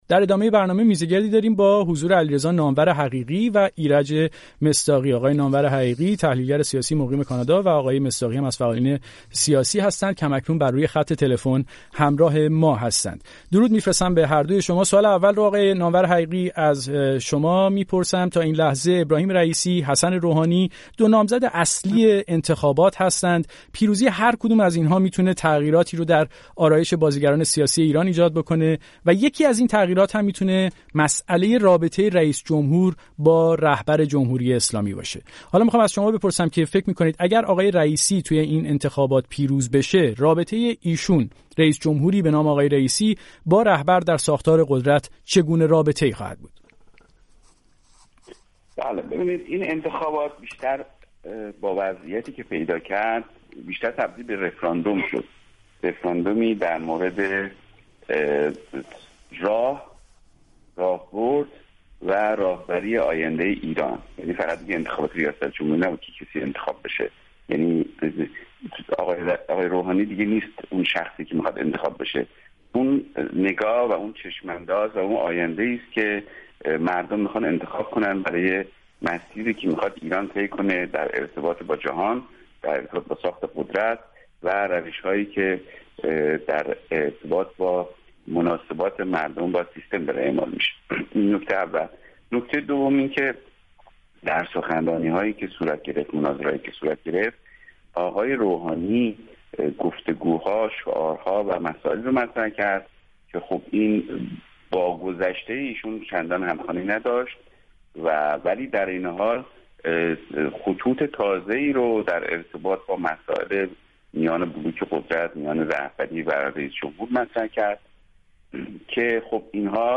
میزگرد رادیویی